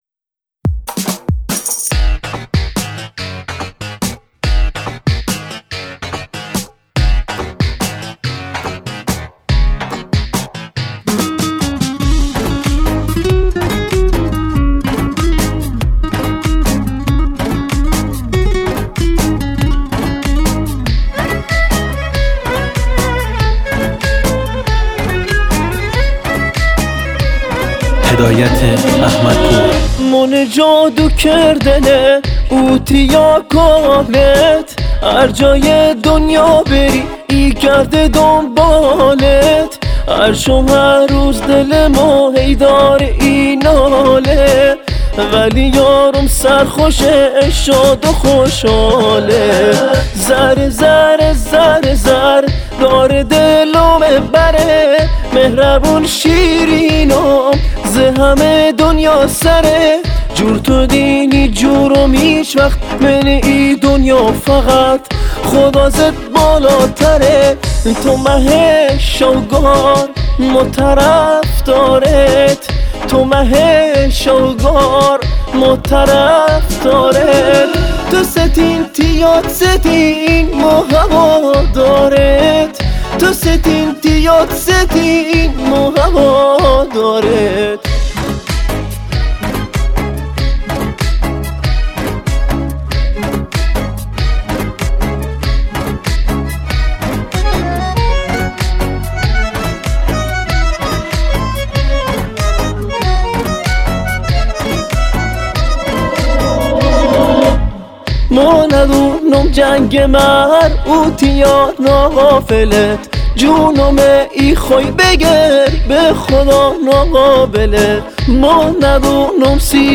آهنگ لری جدید